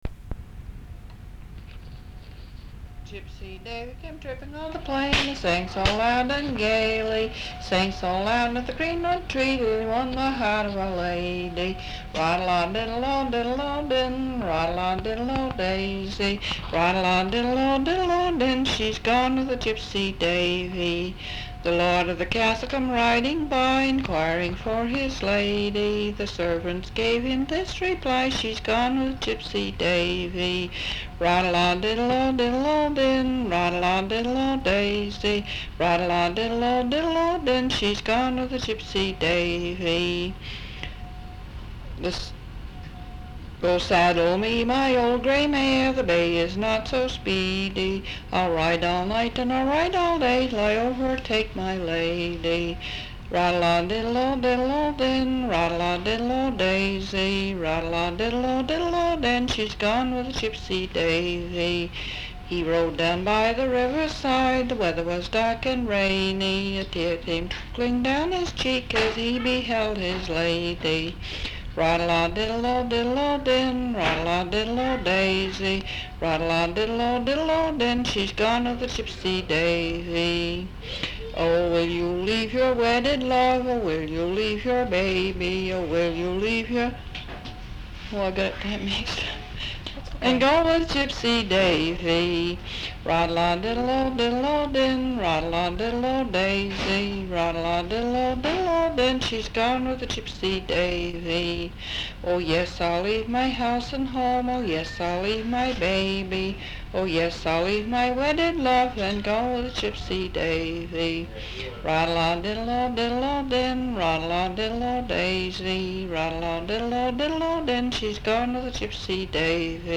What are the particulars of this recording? sound tape reel (analog) Readsboro, Vermont